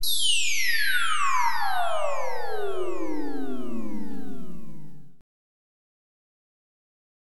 CDK Transition 1.wav